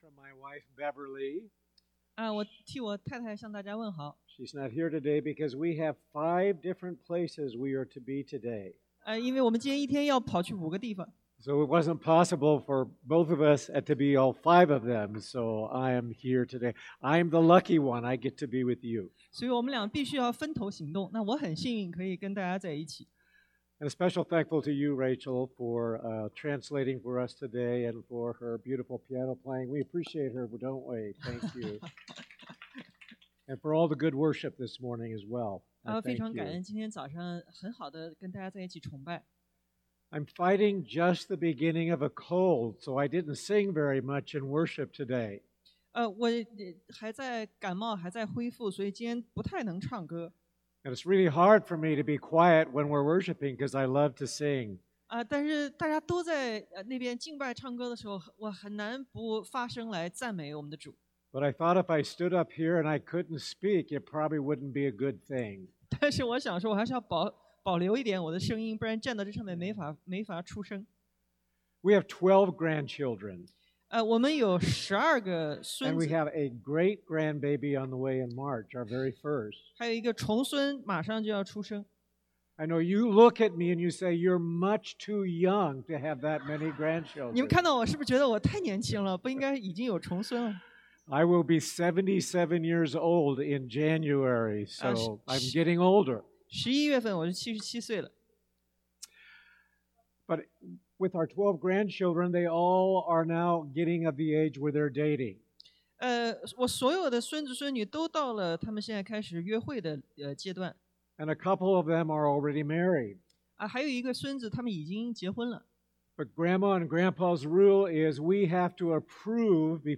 John约翰福音1:1-14 Service Type: Sunday AM JESUS